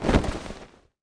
Rolling.mp3